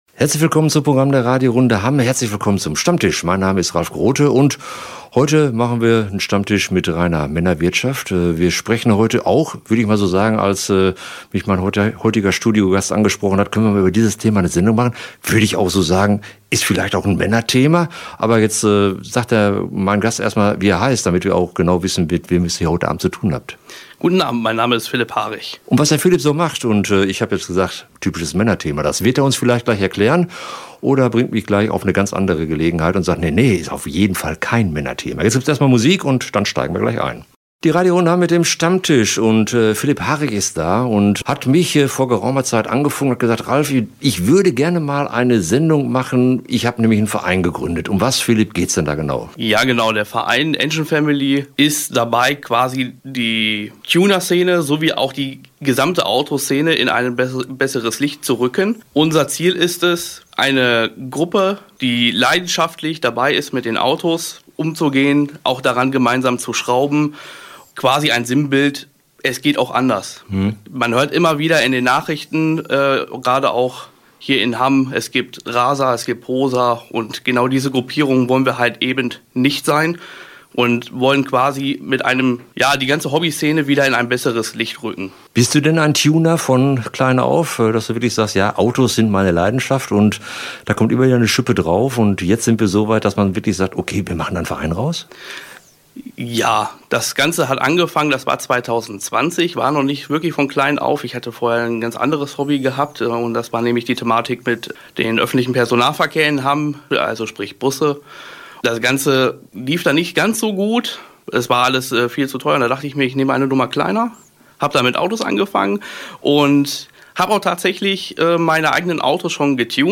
„Stammtisch“ der Radio Runde Hamm in unserem Studio.